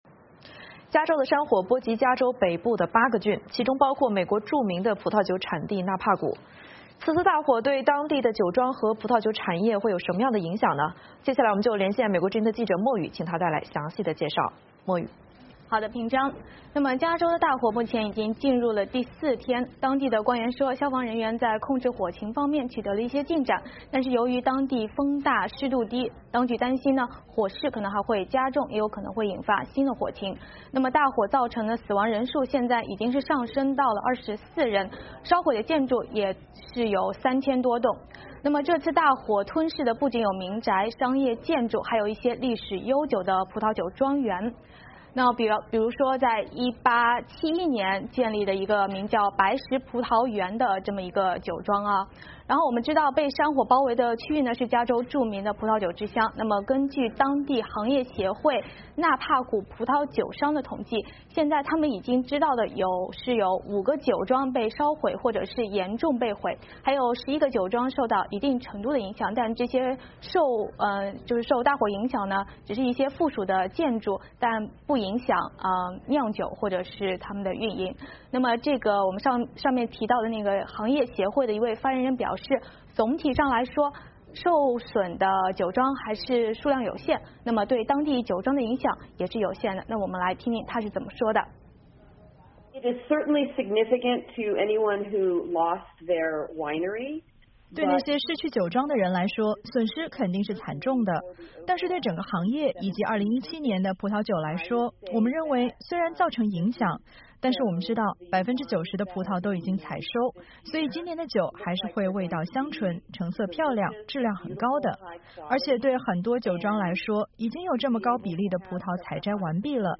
VOA连线：加州大火重创葡萄酒产区